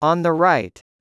16. on the right /ɑːn ðə raɪt/ : bên phải